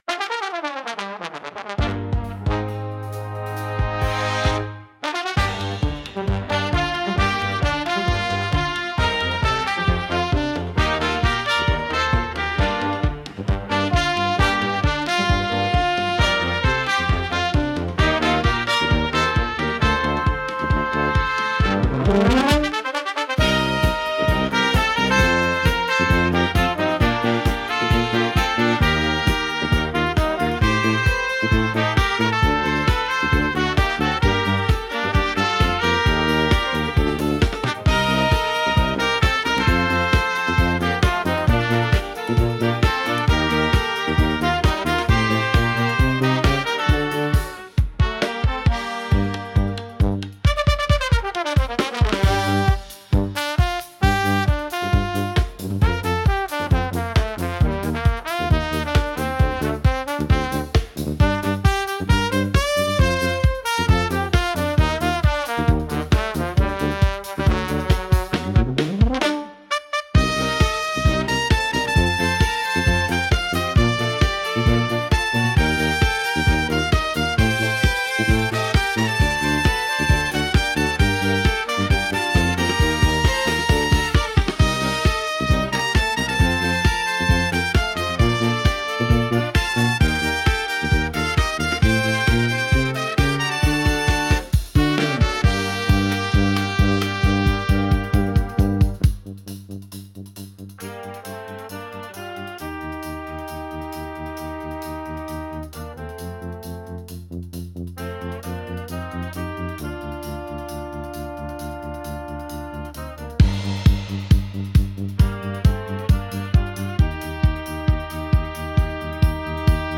ファミコン史上屈指の胸アツサウンドを吹奏楽アレンジしてみました。